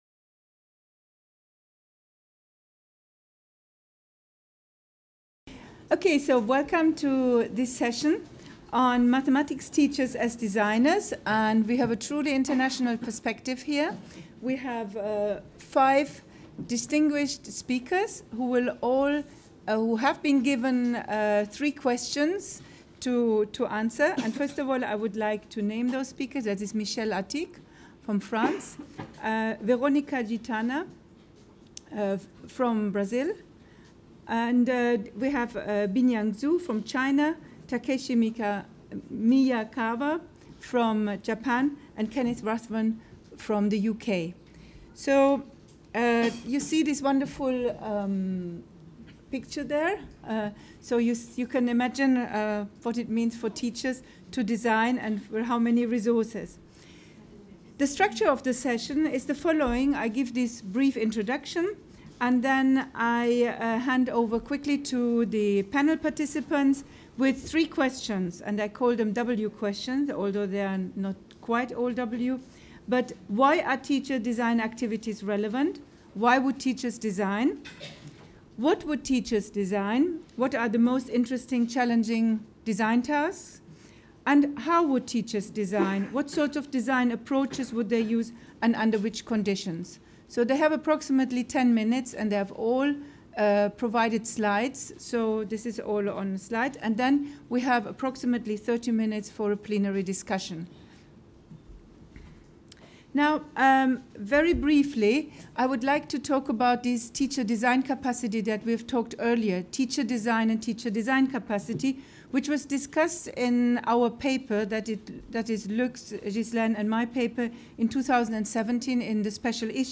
The goal of the panel discussion is to develop deeper understandings of the processes involved when teachers act as designers